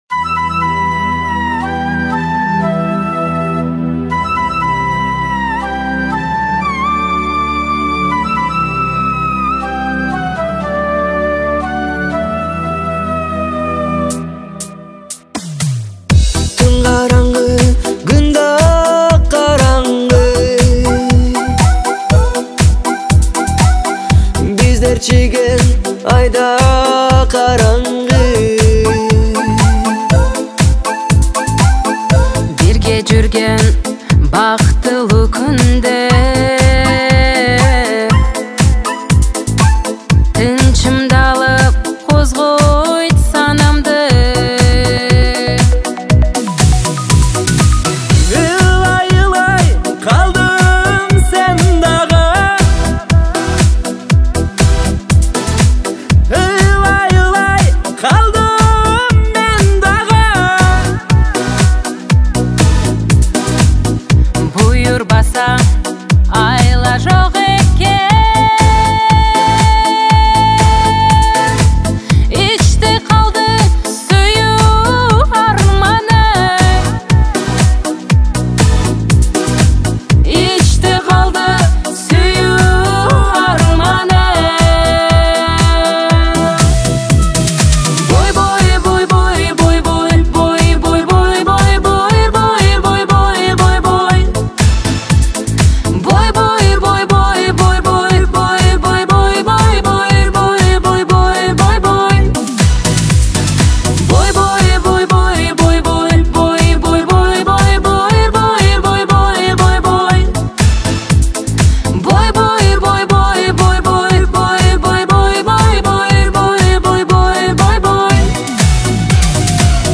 кыргызская песня кавер